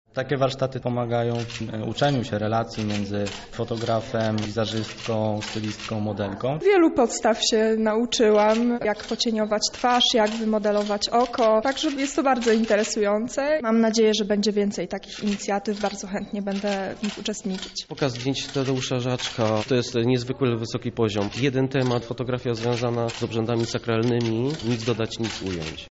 Podczas trzydniowych warsztatów uczestnicy poznali tajniki fotografii otworkowej, techniki mokrego kolodionu oraz sekrety makijażu fotograficznego. Oto jak warsztaty ocenili zainteresowani: